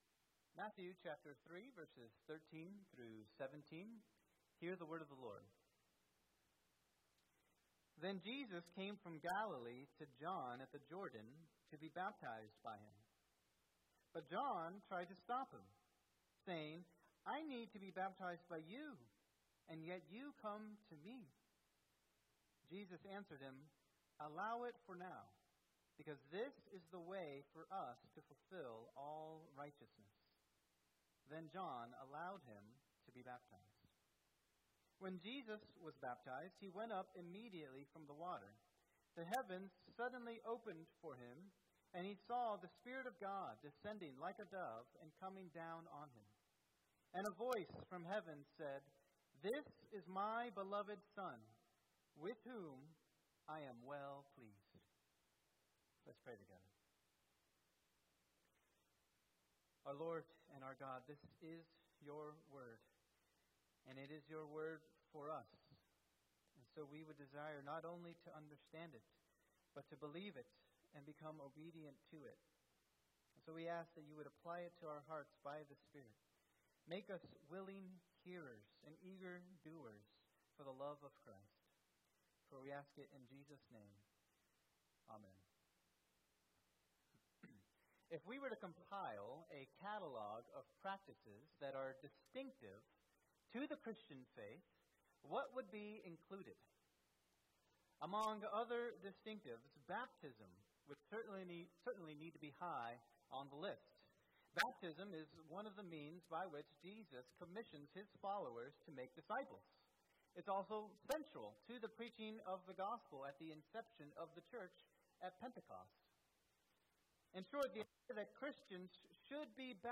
Sermon
2024 at First Baptist Church in Delphi, Indiana.